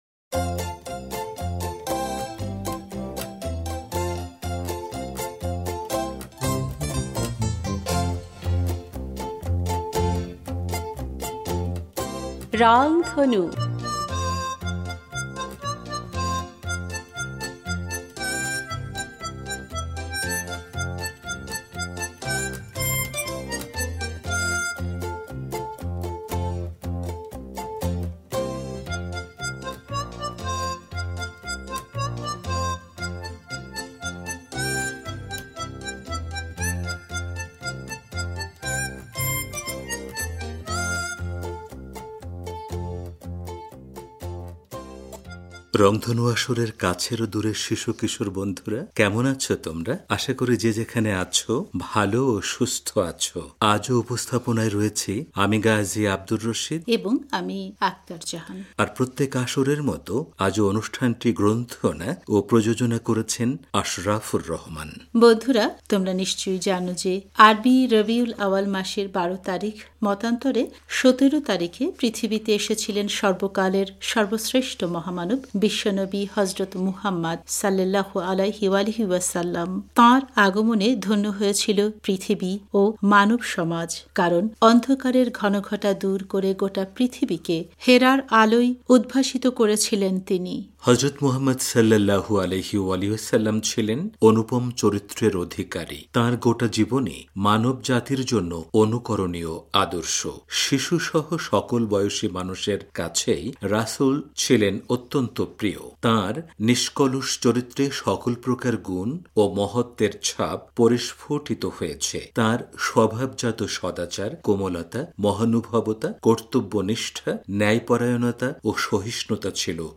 এতে গান , কবিতার পাশাপাশি নবীজির প্রতি ভালোবাসার অনুভূতি প্রকাশ করতে অংশ নিয়েছে ইরান ও বাংলাদেশের চারজন বন্ধু।